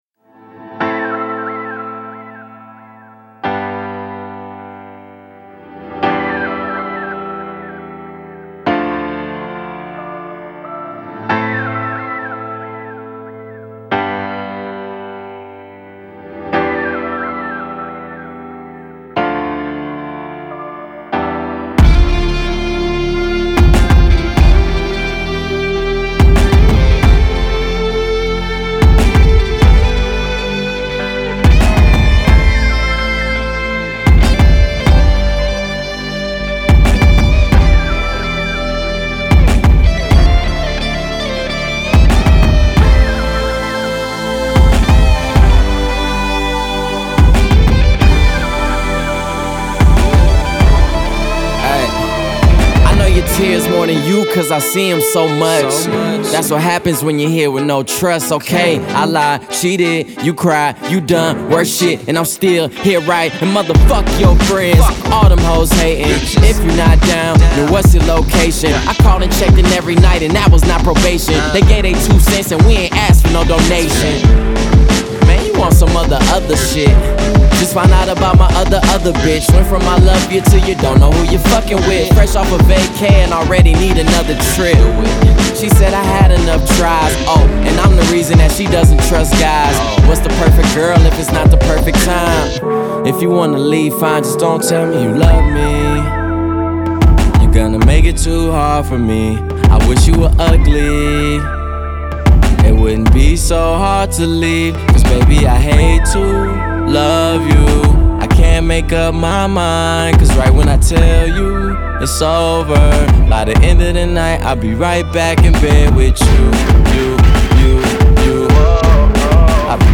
Genre : Rap, Hip Hop